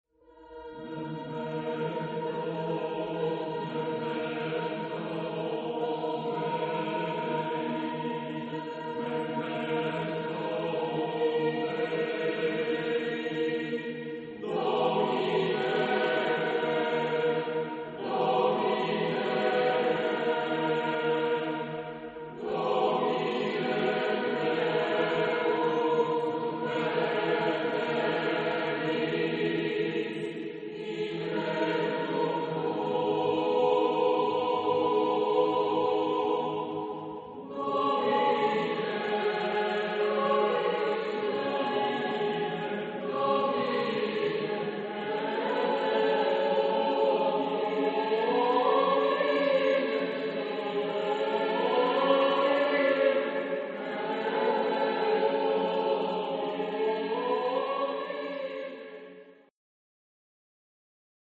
Genre-Style-Forme : Motet ; Sacré
Type de choeur : SSAATTBB  (8 voix mixtes )
Tonalité : atonal
Consultable sous : 20ème Sacré Acappella